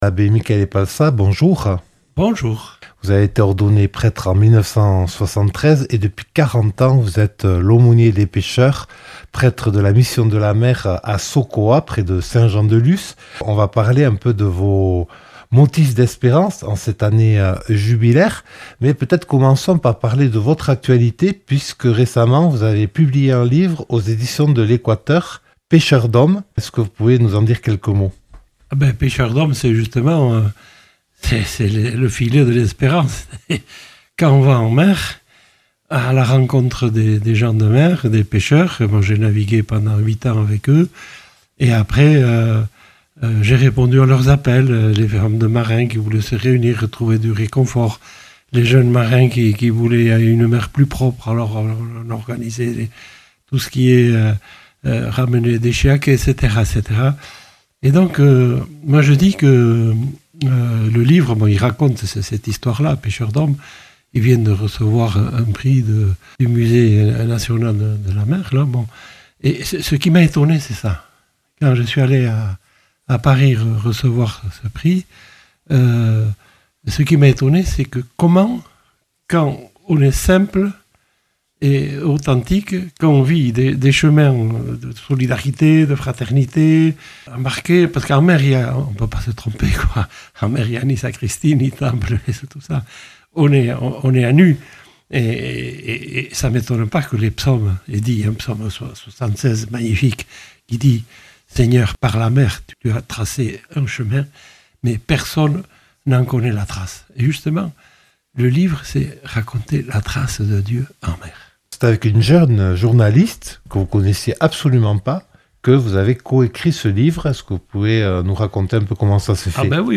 Rencontre avec l'aumônier des marins